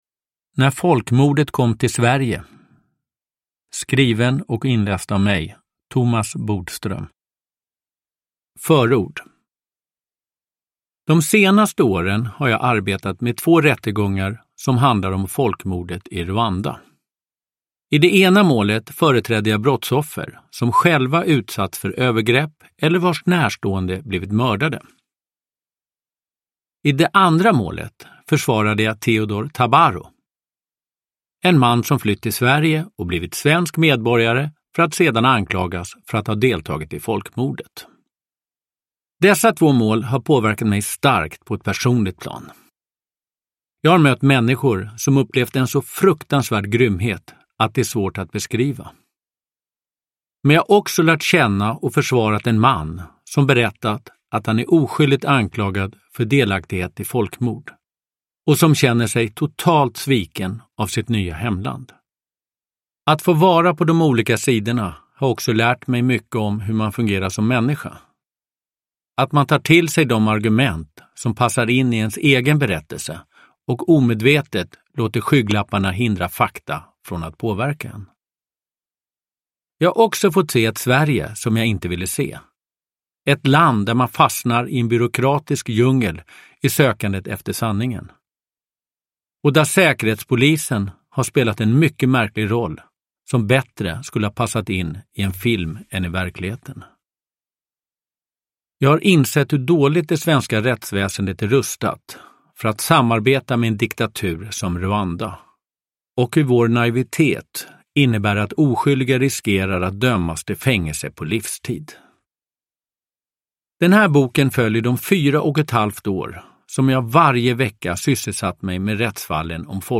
Uppläsare: Thomas Bodström